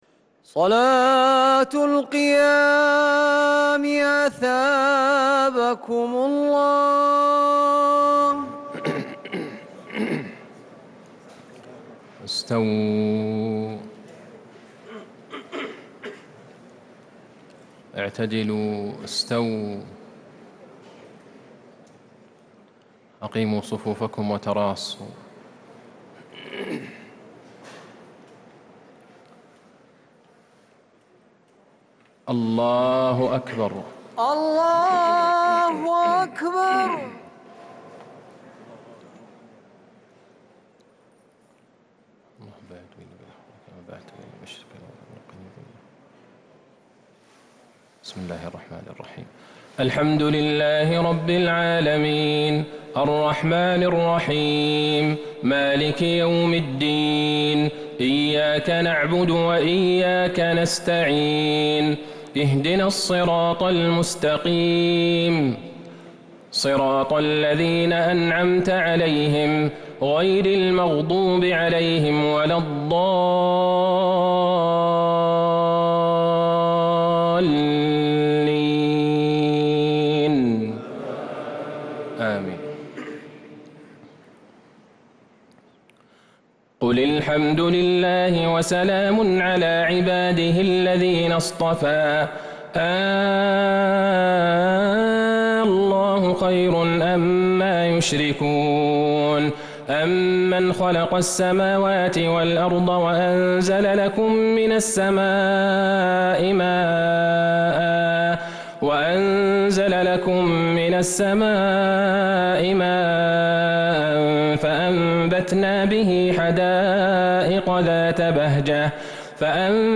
صلاة التراويح ليلة 23 رمضان 1443 للقارئ عبدالله البعيجان - الثلاث التسليمات الاولى صلاة التهجد